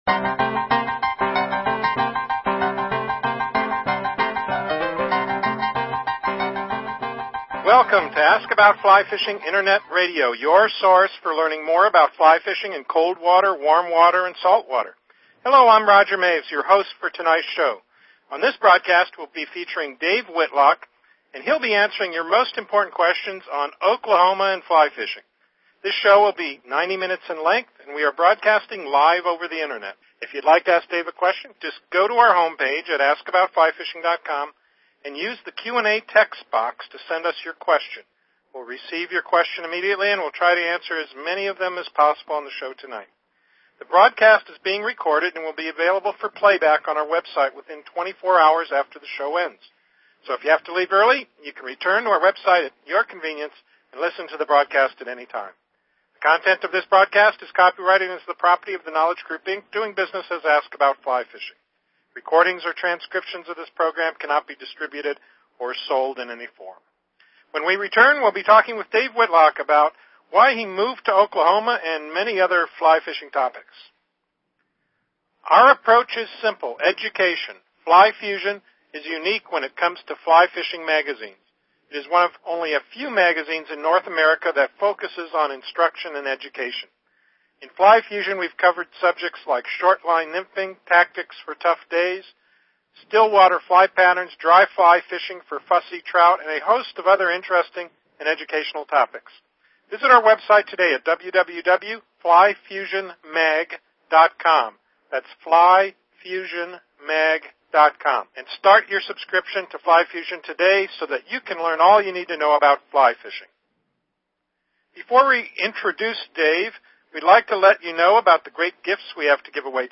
You won't want to miss this interview of one of fly fishing's most notable figures.